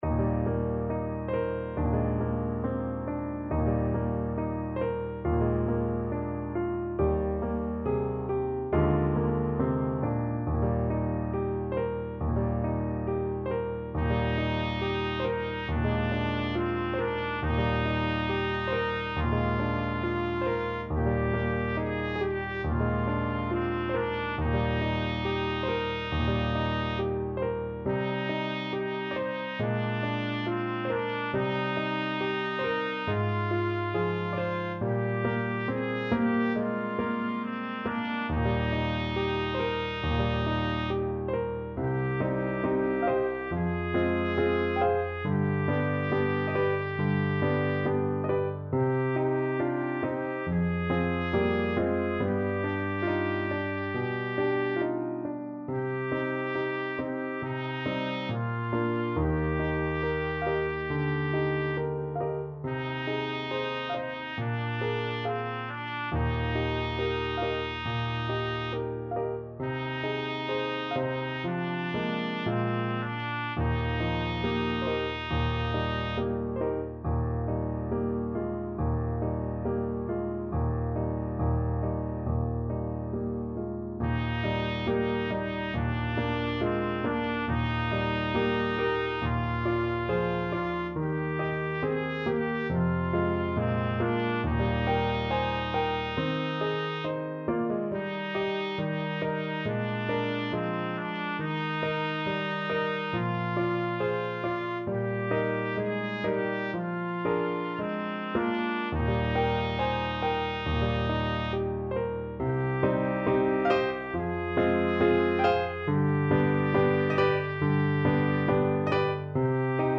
Trumpet version
4/4 (View more 4/4 Music)
~ = 69 Andante tranquillo
Classical (View more Classical Trumpet Music)